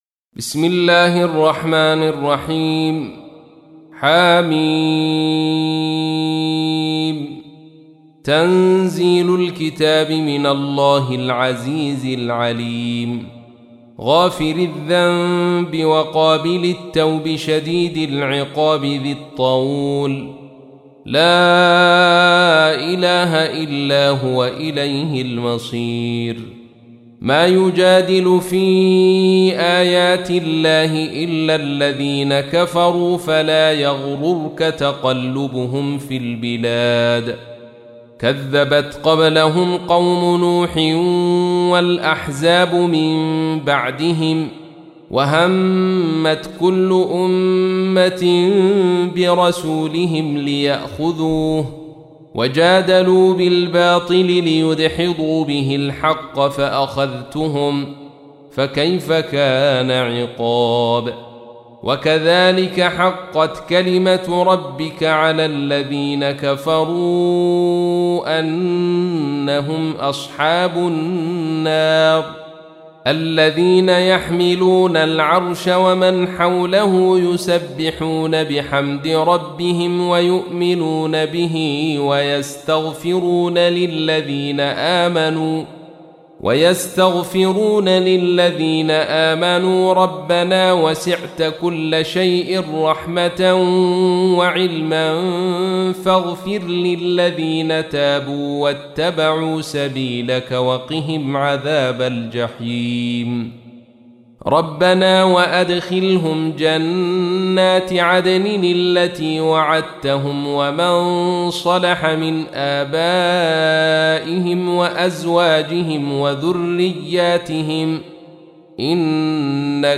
تحميل : 40. سورة غافر / القارئ عبد الرشيد صوفي / القرآن الكريم / موقع يا حسين